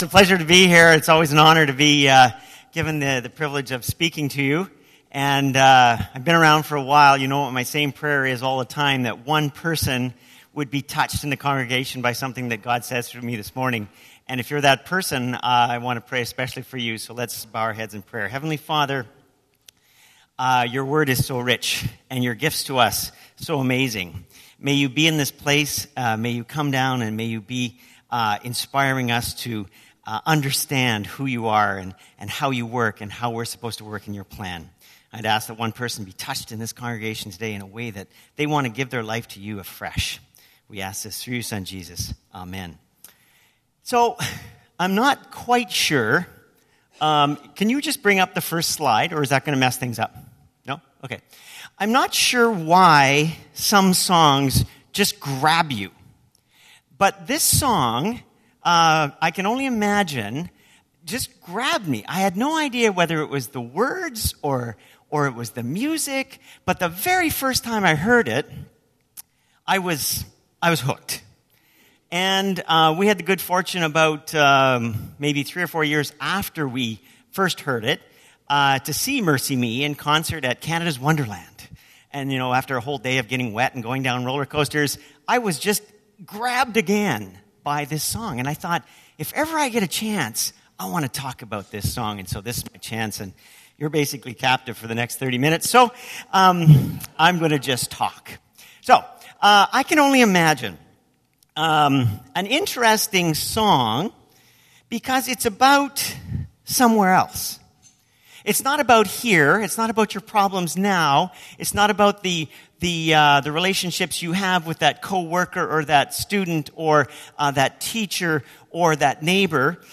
This sermon is based on the song “I Can Only Imagine.”